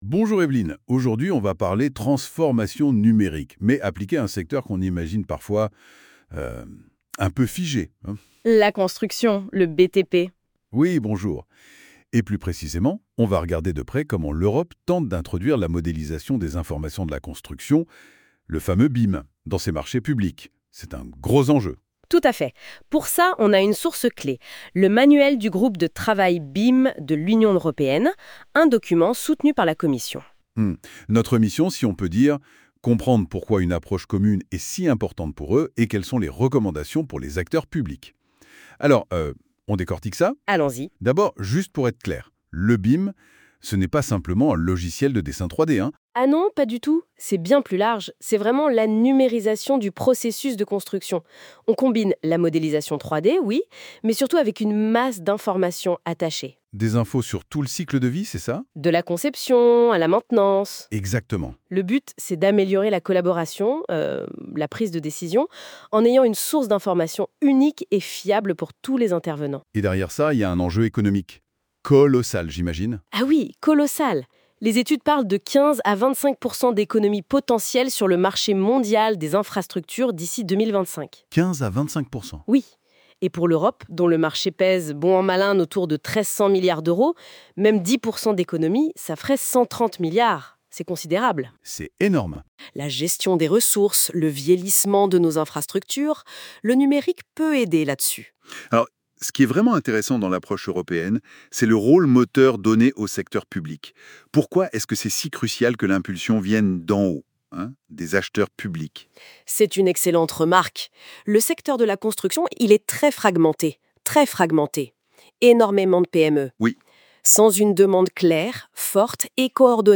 [!Success]Ecoutez la conversation !